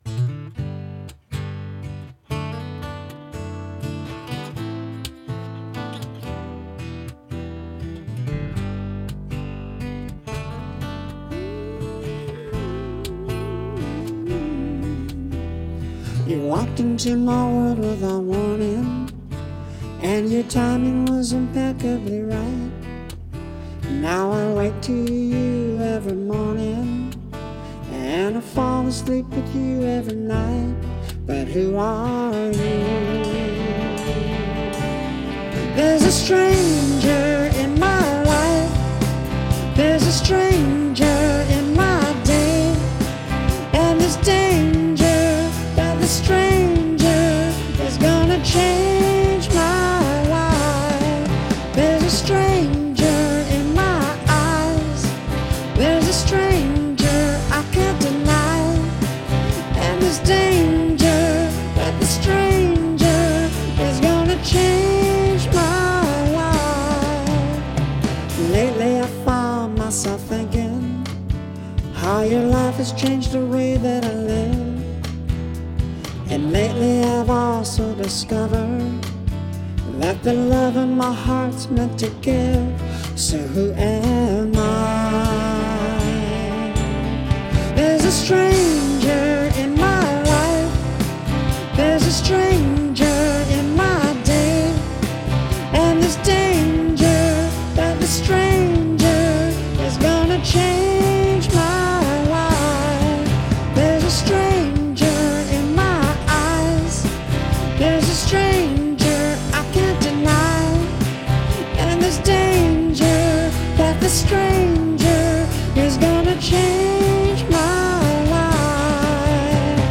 Key of G - Track with Reference Vocal